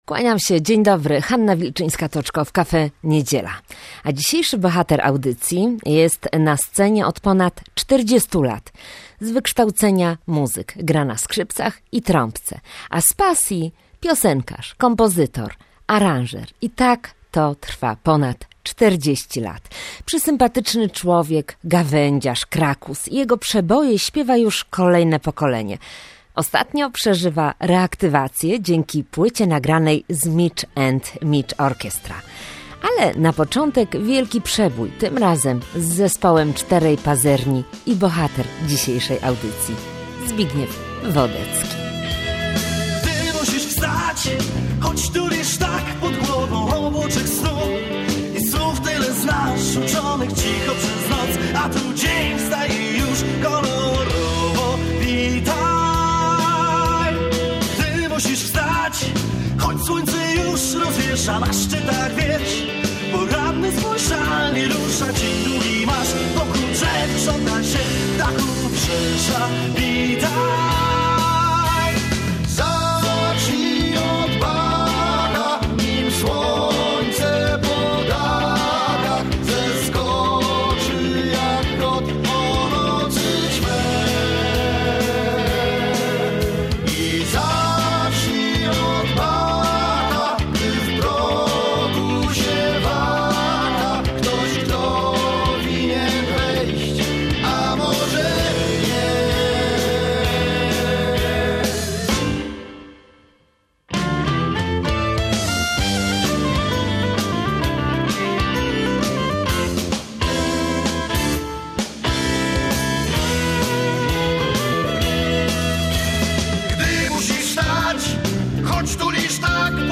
Gościem Cafe Niedzieli 17 stycznia był Zbigniew Wodecki - znany muzyk, tegoroczny zdobywca 2 Fryderyków w kategorii album roku pop